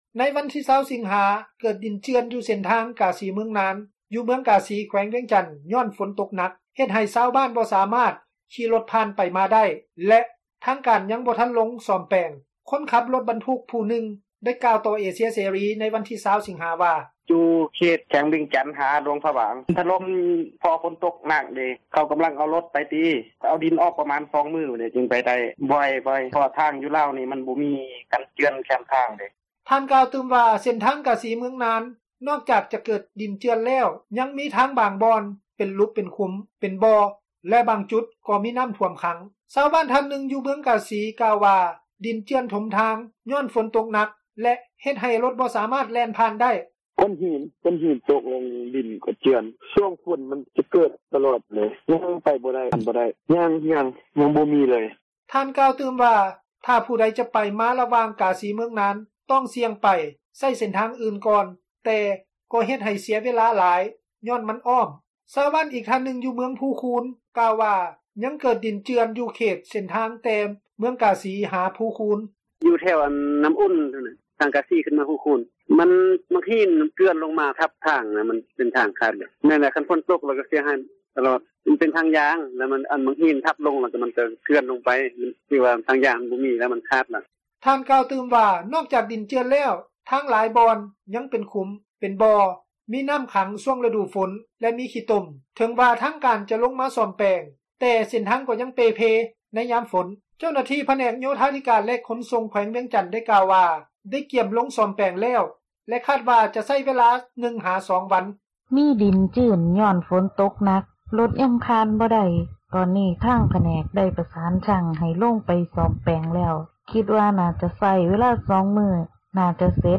ຄົນຂັບລົດບັນທຸກຜູ້ນຶ່ງ ໄດ້ກ່າວຕໍ່ເອເຊັຽເສຣີ ໃນວັນທີ 20 ສິງຫາ ວ່າ: